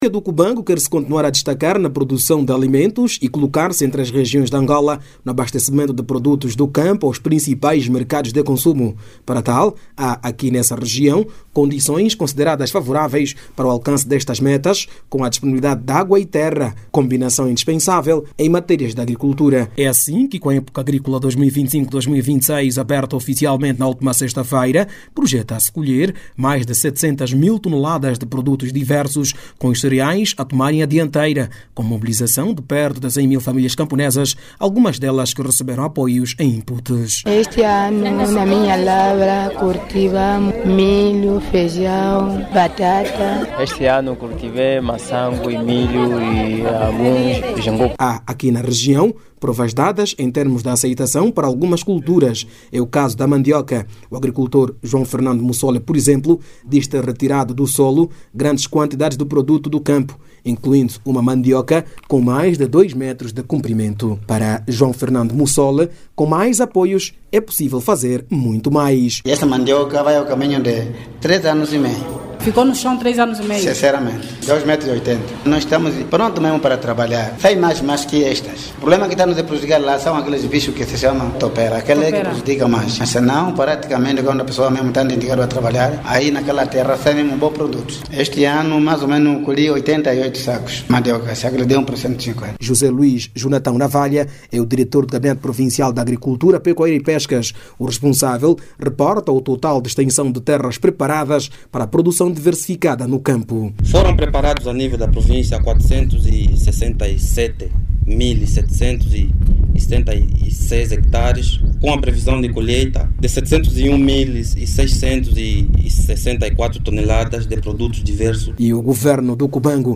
A reportagem é do